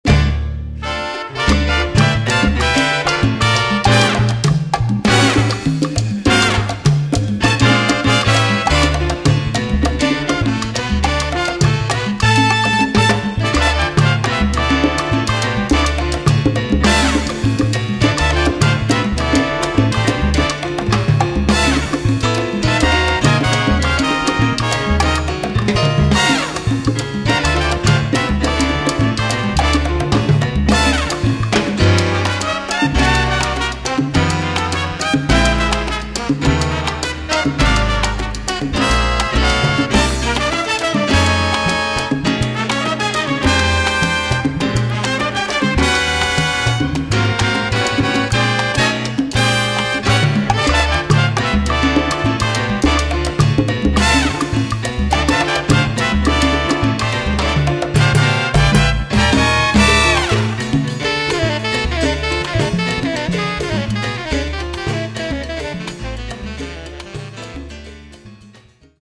Category: combo (septet)
Style: mambo
Solos: open